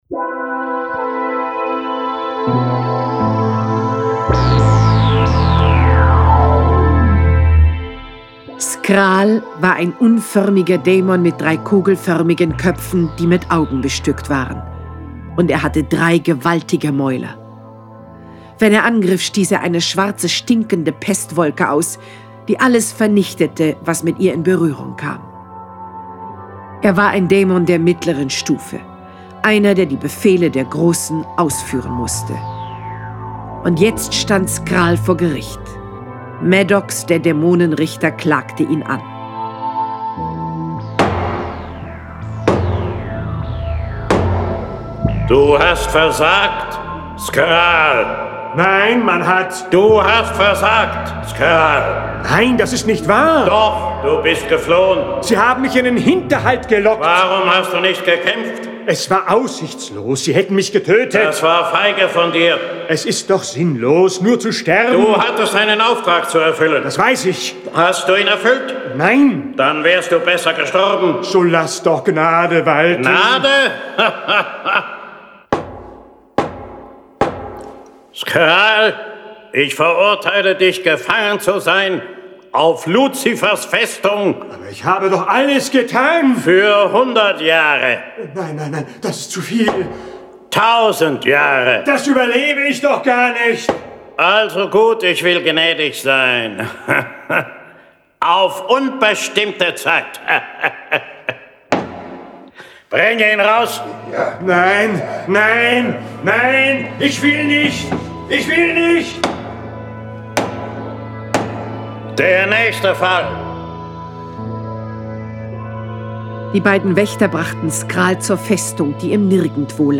John Sinclair Tonstudio Braun - Folge 59 Luzifers Festung. Jason Dark (Autor) diverse (Sprecher) Audio-CD 2017 | 1.